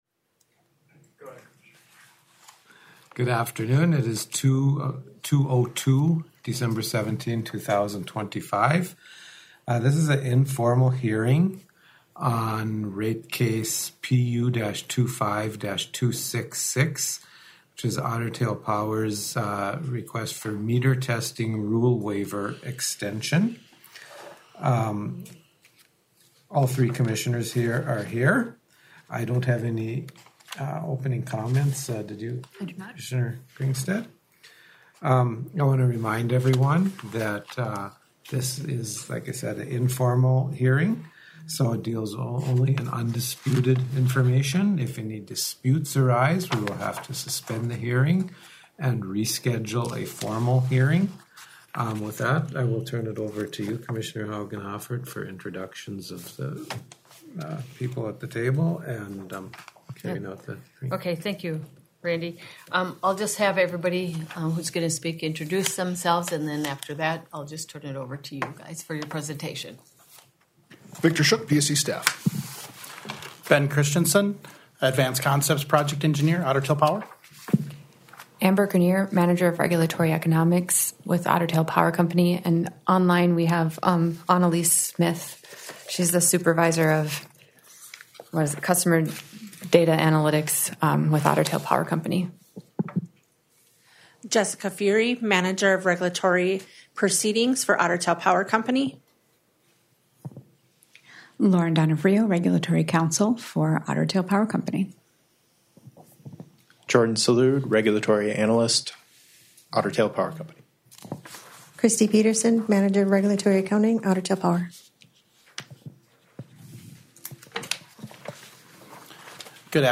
PU-25-266.8 Electronic Recording of 17 December 2025 Informal Hearing